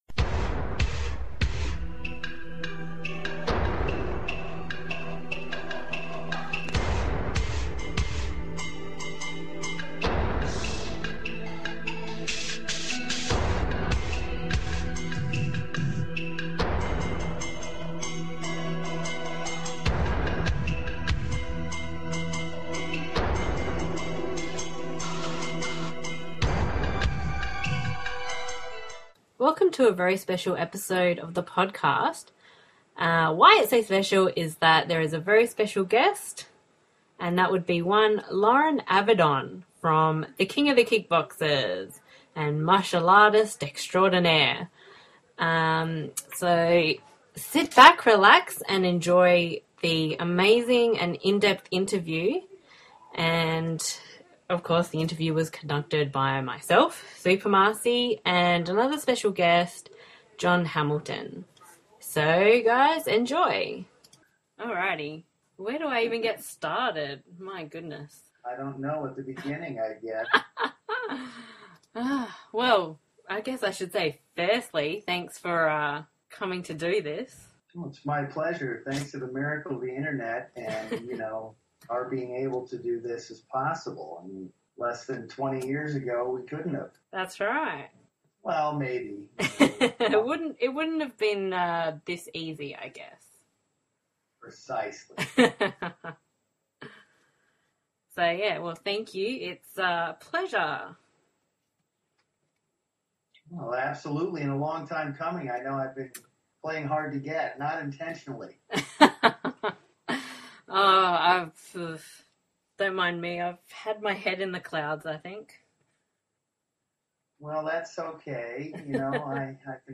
Interview: Loren Avedon
the-loren-avedon-interview.mp3